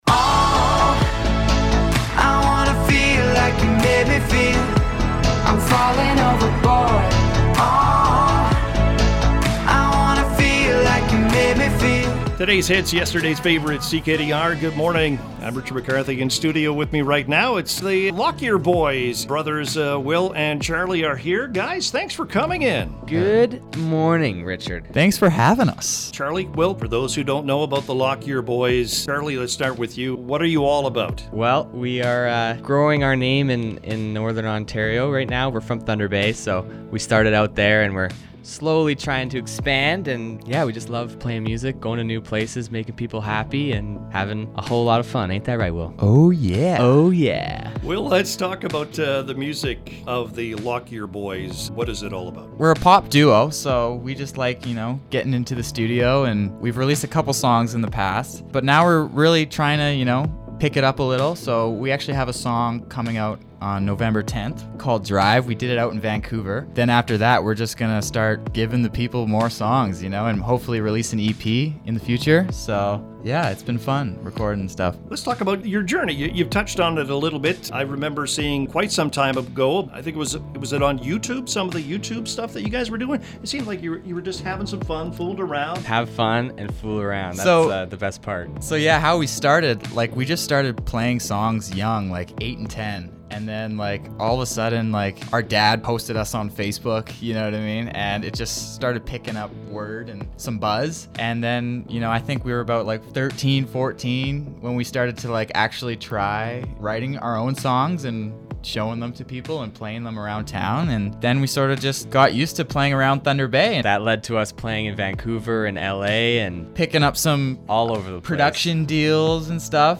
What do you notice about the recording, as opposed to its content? An amazingly talented pop duo from Thunder Bay were in Dryden at CKDR Studios!